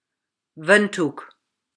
Windhoek (/ˈwɪndhʊk/; Afrikaans: [ˈvəntɦuk]
Af-Windhoek.oga.mp3